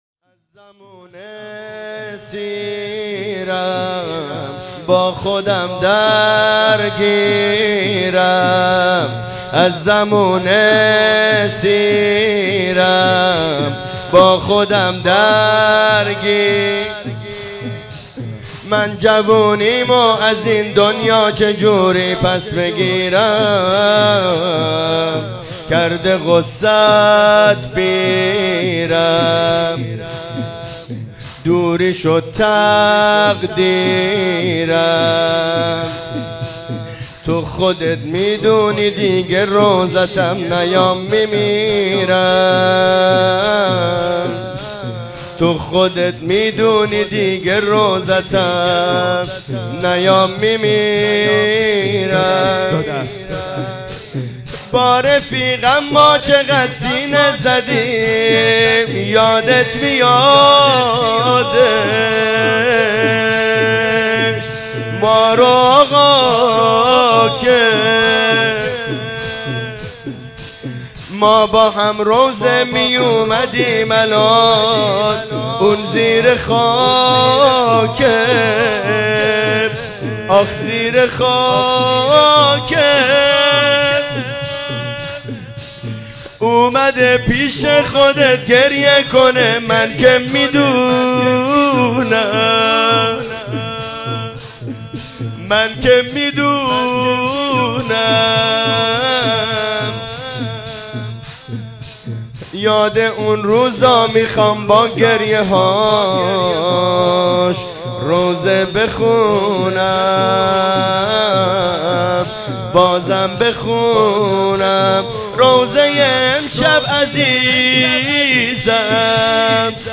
جلسه هفتگی شهدای مدافع امنیت